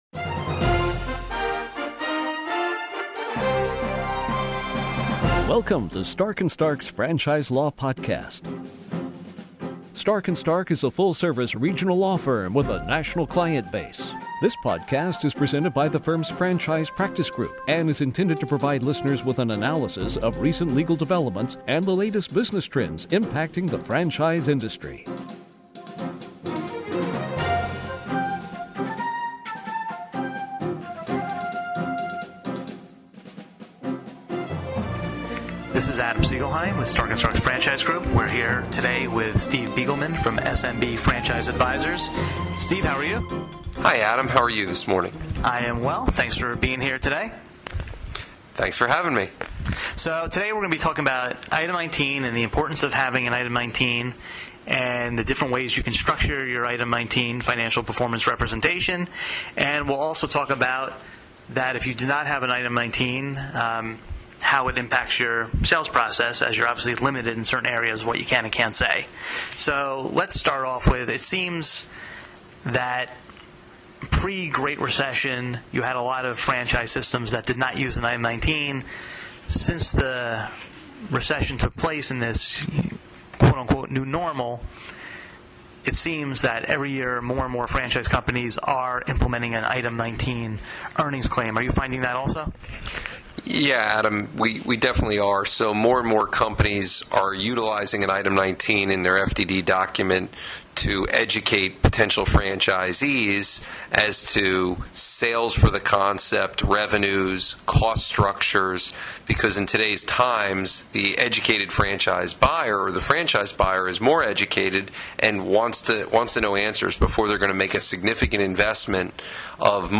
In this installment of the Franchise Law Podcast series